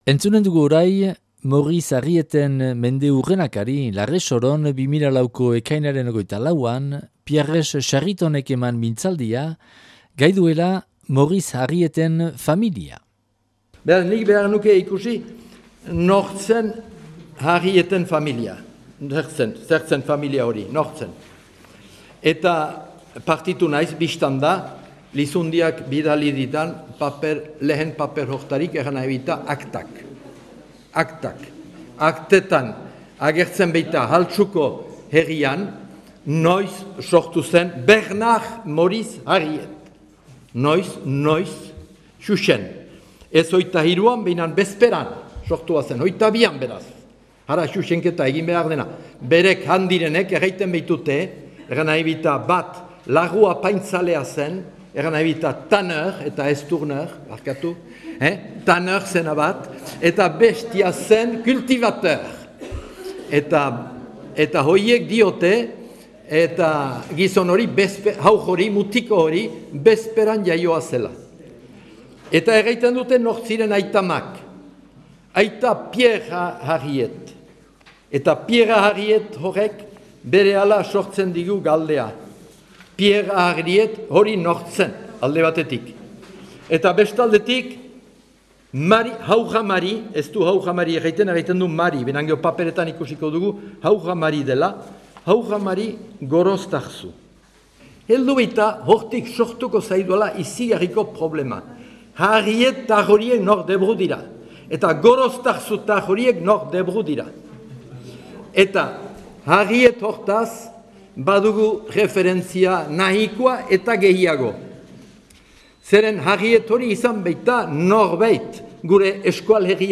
(Larresoron grabatua 2004. ekainaren 4an).